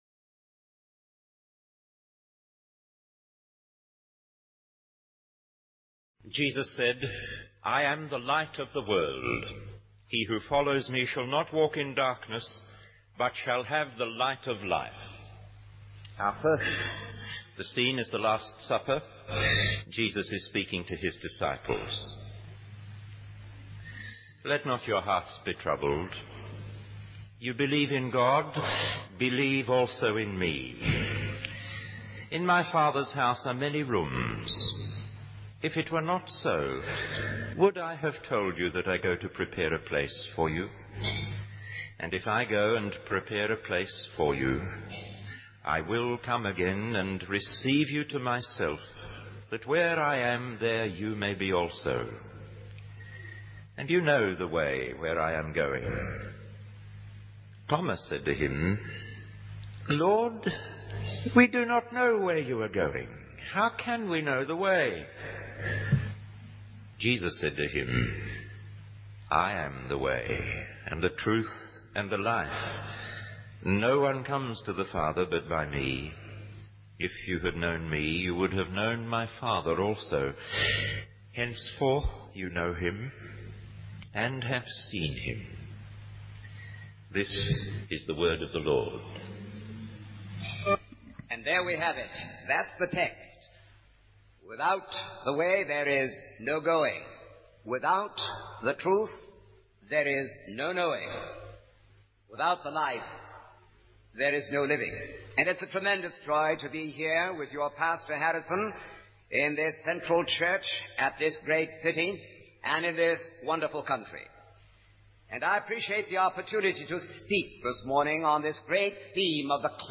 In this sermon, the preacher emphasizes that the world is lost and in need of salvation. He explains that Jesus came to seek and save the lost, and that He is the way to God.